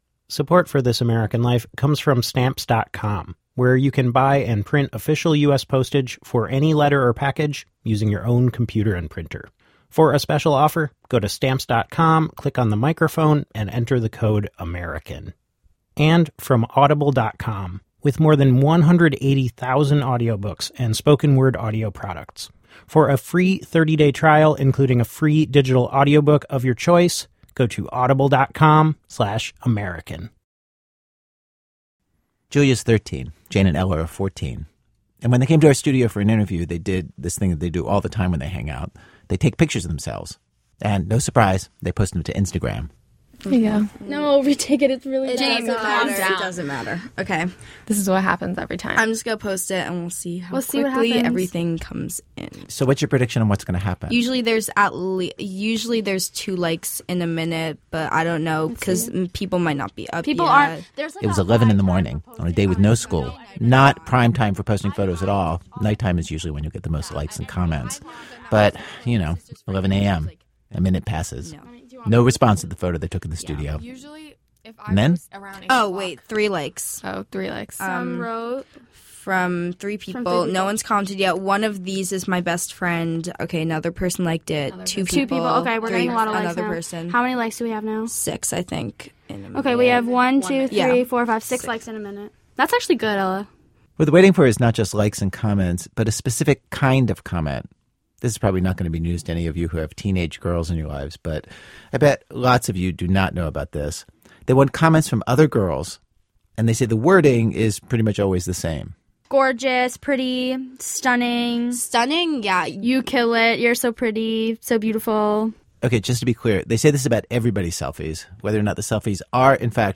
This week, status updates that interrupt daily life. We hear two friends talk about how one of them has become rich and famous. And an entire town gets a status update on itself.